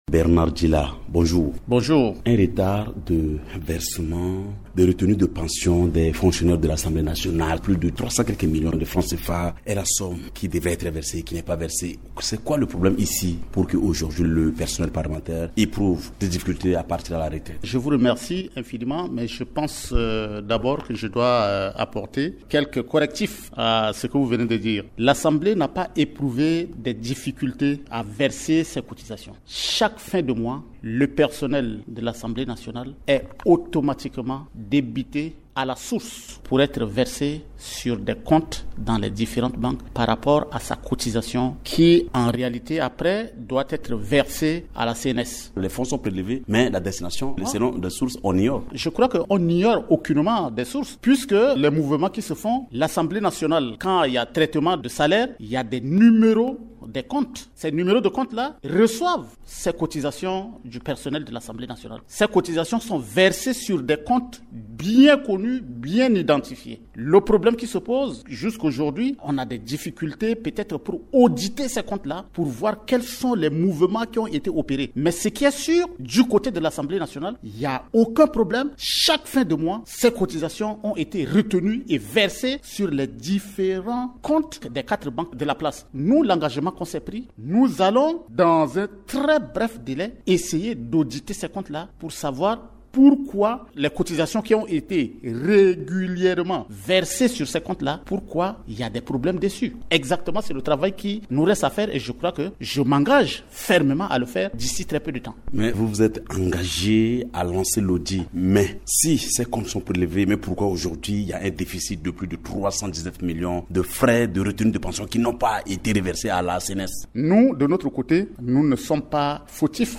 Au cours d’une entrevue à Radio Ndeke Luka, Bernard Dillah, 2e vice président de l’Assemblée Nationale, revient sur les retenus de pension du personnel administratif prélevés sur les salaires qui n’ont pas été versés à la Caisse nationale de sécurité sociale (CNSS) dont le montant total s’élève à 319 millions de FCFA. Il annonce qu’un audit sera lancé à cet effet.